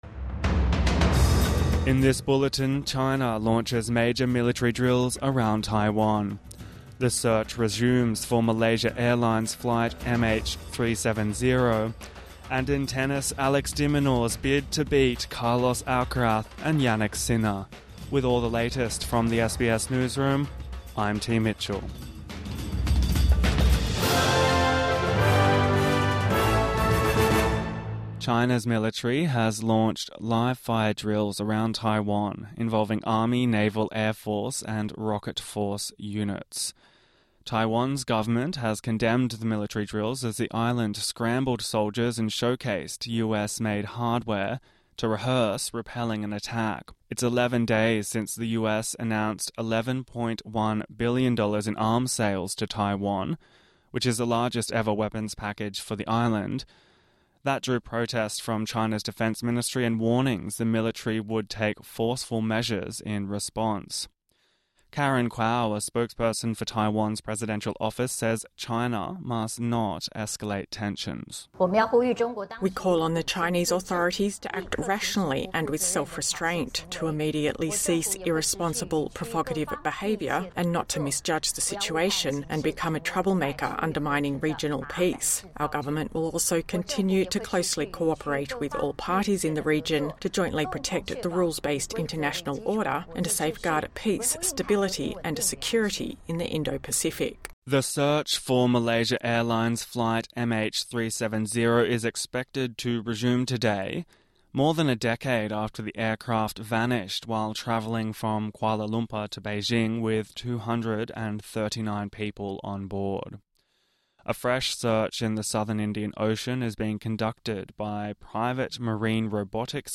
China Launches Military Drills Around Taiwan | Morning News Bulletin 30 December 2025